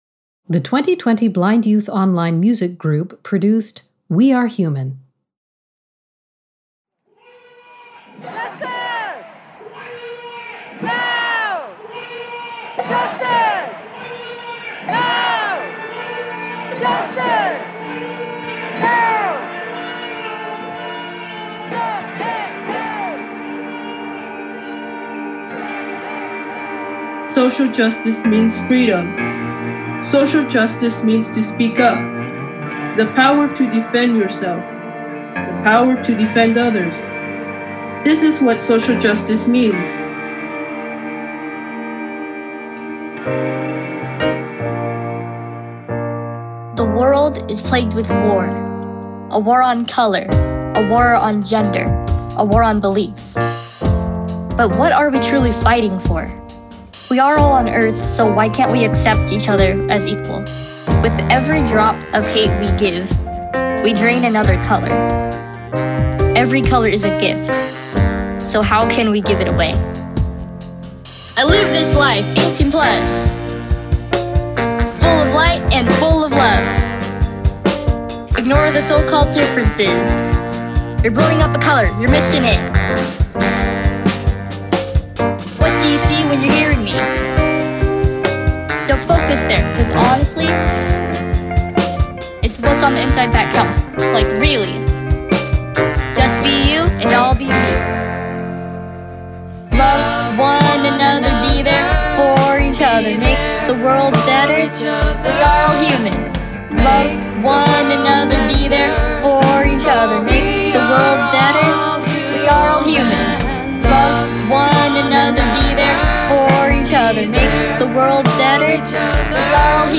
We offered three workshops: Music and Song, Radio Drama, and Rap Lyrics and Vocals.
collaborated with one group of students to write and produce an original song
3_Music_We-Are-Human_MASTER.mp3